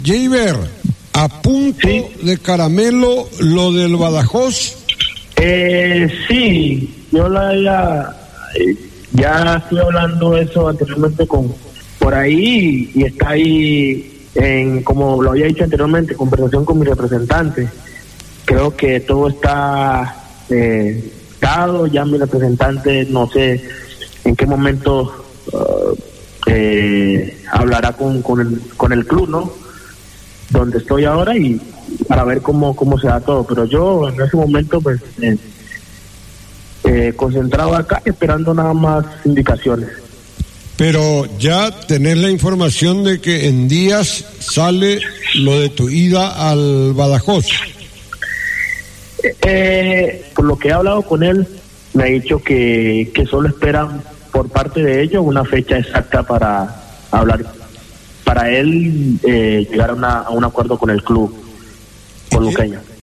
El jugador señala en una radio de Luque (Paraguay) que "Solo queda hablar con el Luqueño para cerrar el día que me voy"